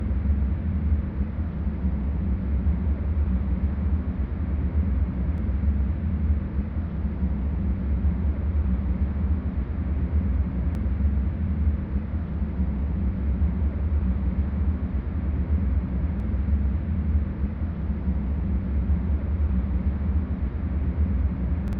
Звуки дуновения
Звук пустынного ветра у палатки